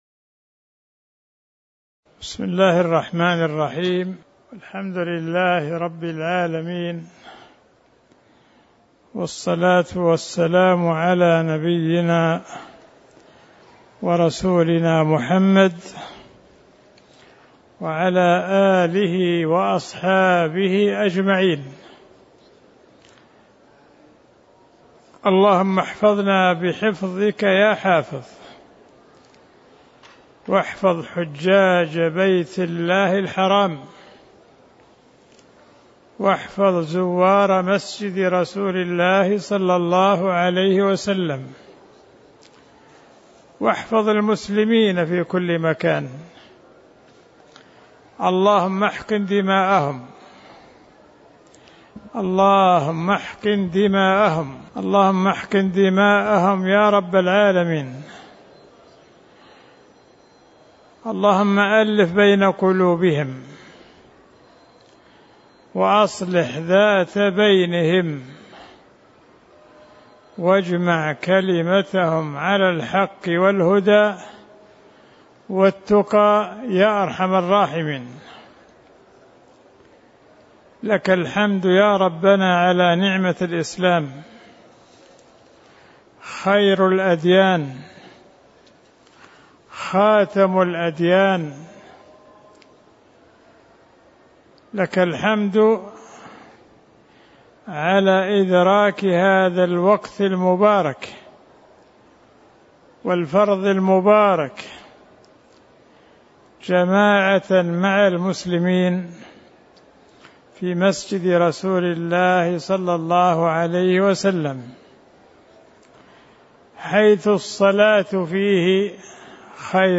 تاريخ النشر ٩ ذو الحجة ١٤٤٠ المكان: المسجد النبوي الشيخ